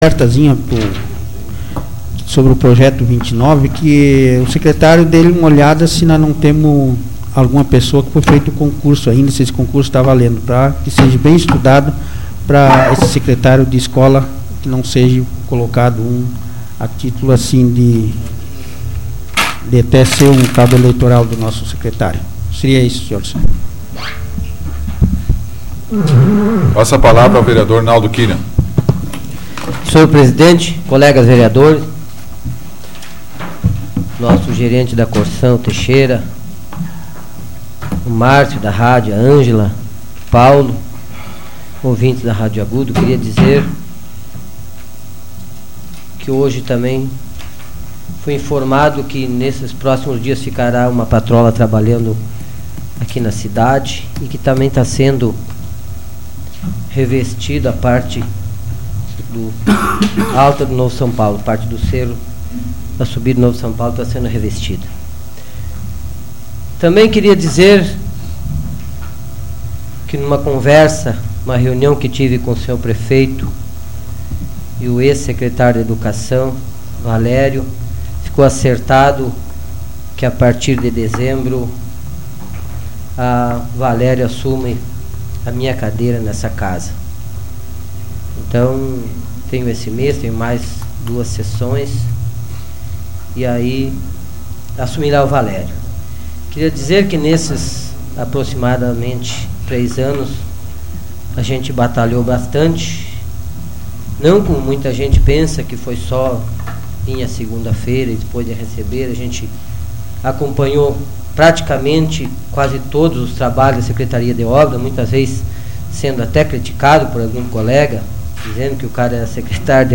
Áudio da 43ª Sessão Plenária Extraordinária da 12ª Legislatura, de 12 de novembro de 2007